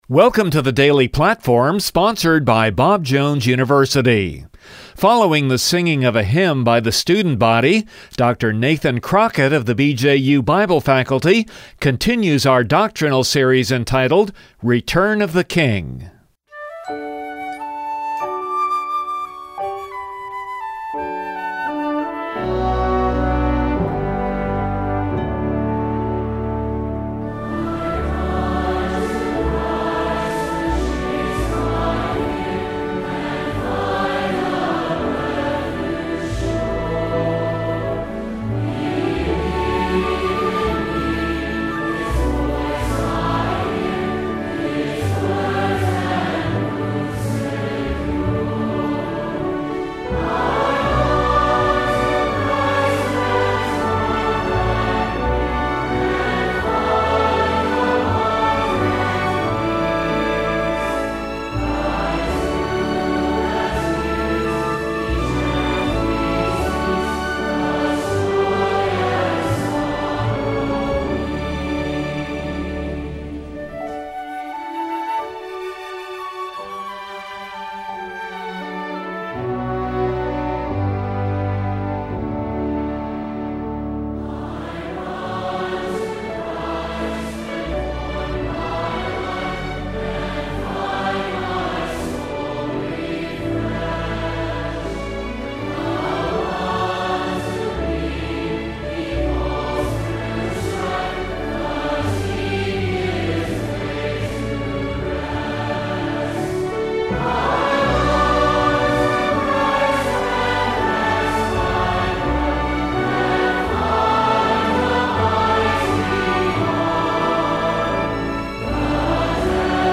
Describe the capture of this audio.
The Signs of the Times Matthew 24 From the chapel service on 01/30/2019 Download Share this Post